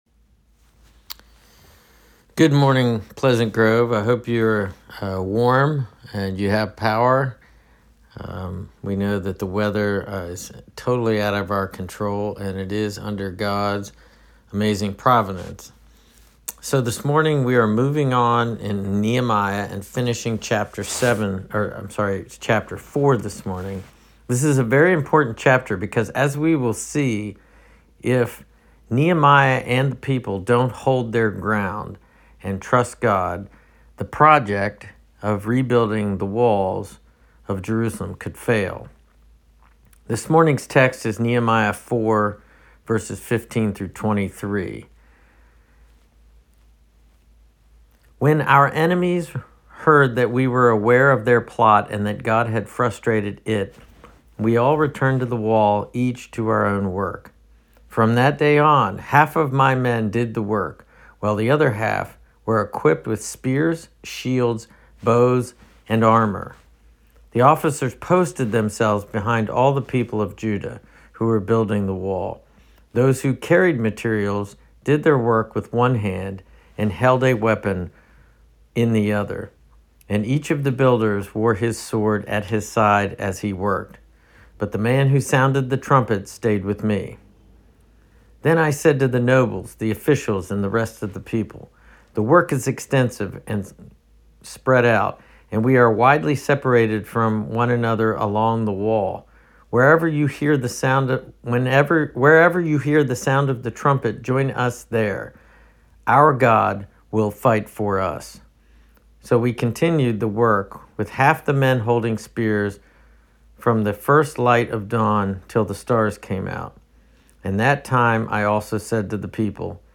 Nehemiah-4-Sermon.mp3